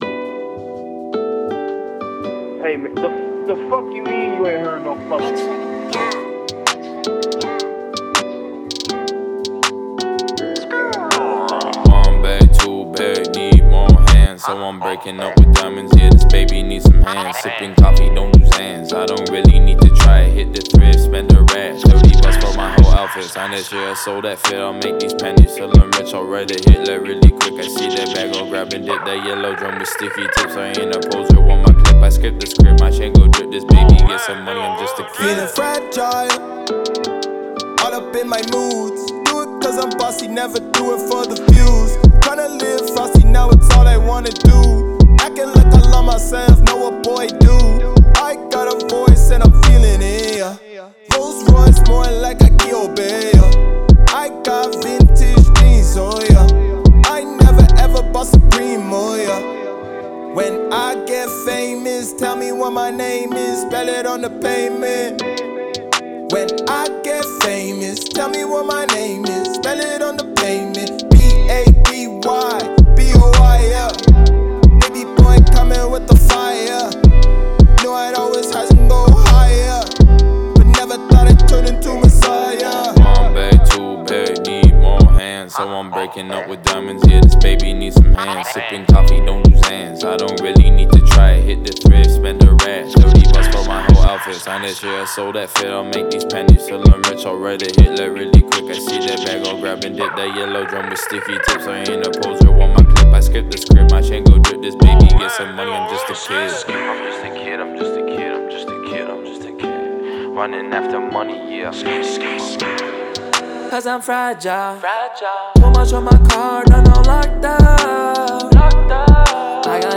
это яркая и динамичная композиция в жанре хип-хоп